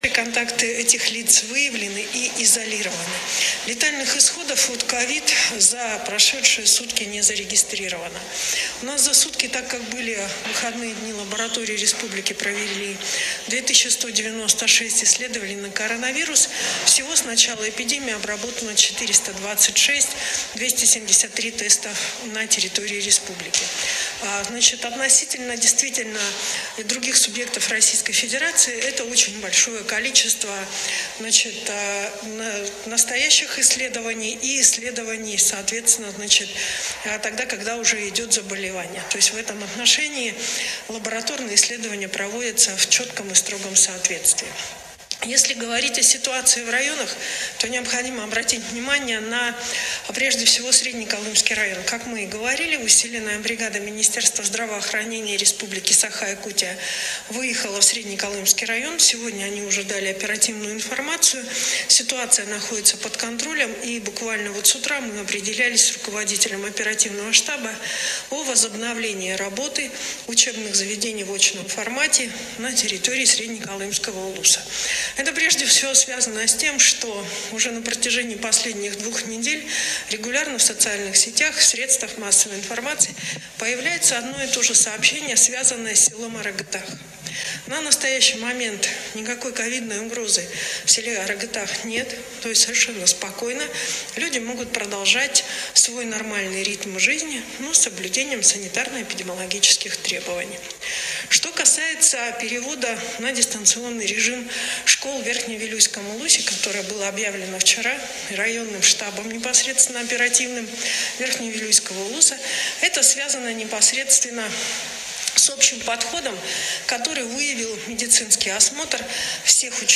Данные на сегодняшний день озвучила вице-премьер Ольга Балабкина
Какова эпидемиологическая обстановка в республике по состоянию на 14 сентября, рассказала вице-премьер региона Ольга Балабкина.